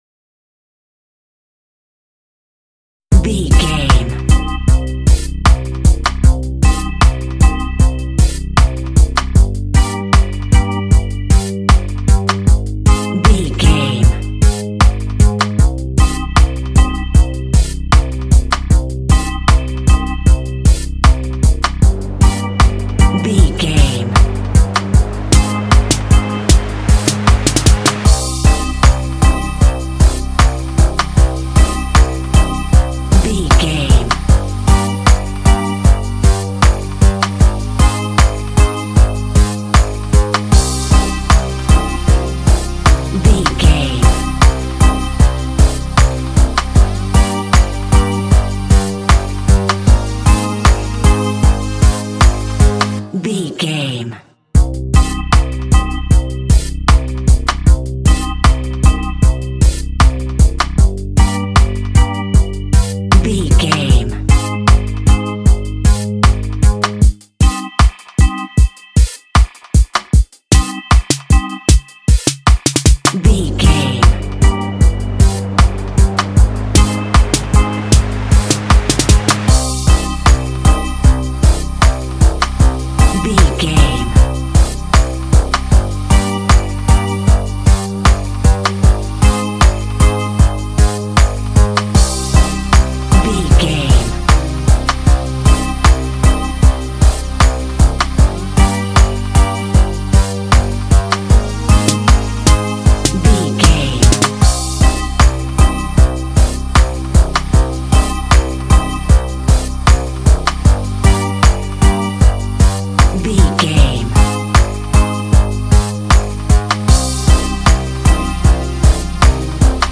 Aeolian/Minor
laid back
off beat
skank guitar
hammond organ
horns